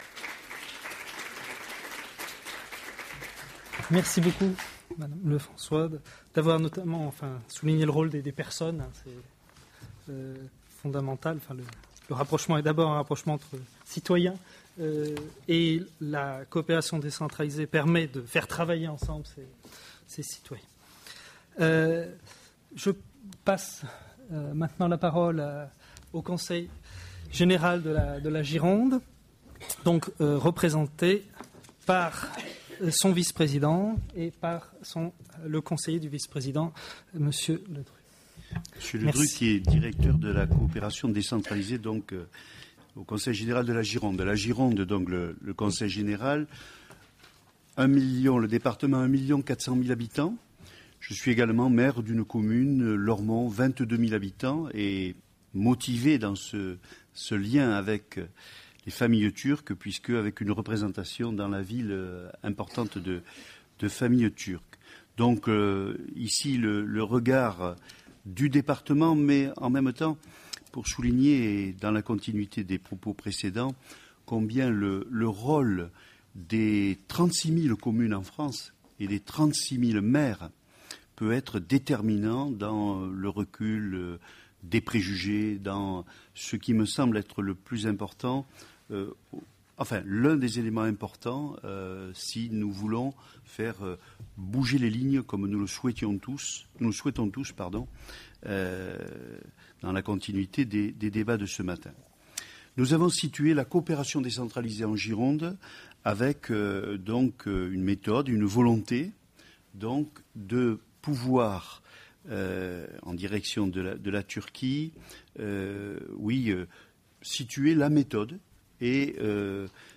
Le 24 octobre 2009 Turquie Européenne a organisé dans le cadre de la Saison de la Turquie en France, une conférence-débat :